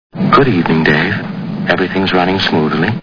Movie Sound Bites